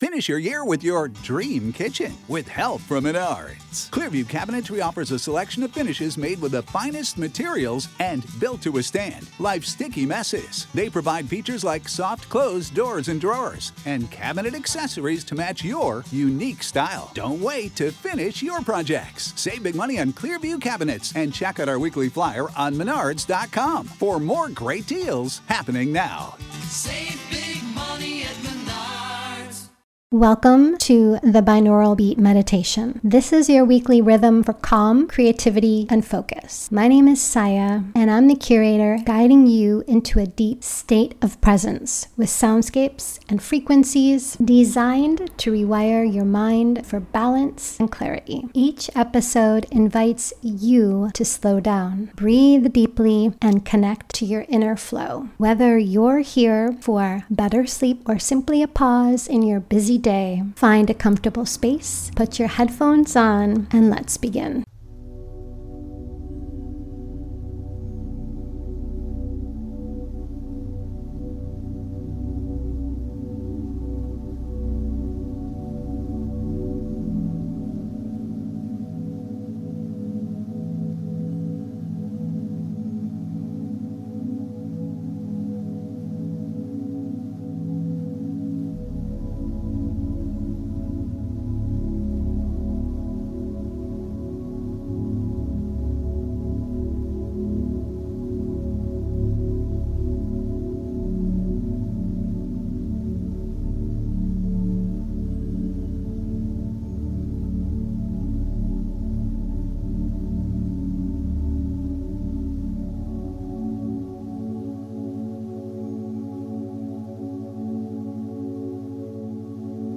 Mindfulness and sound healing — woven into every frequency.